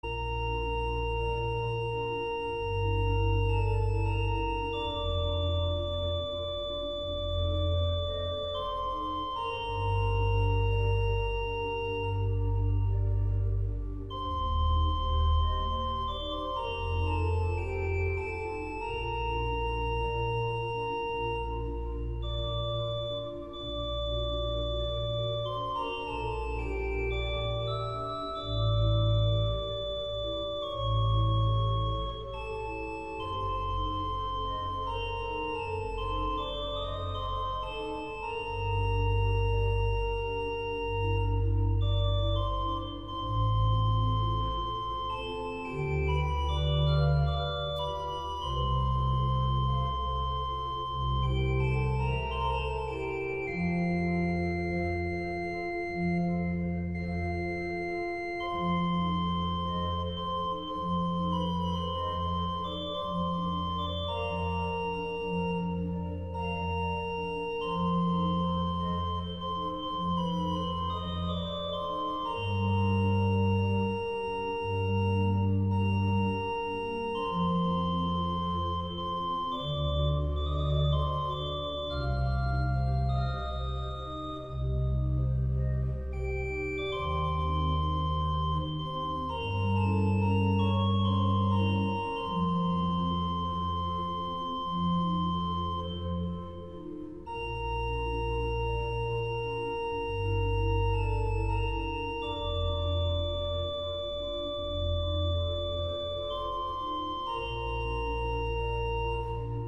Музыка была медленная плавная, похожая немного на церковную.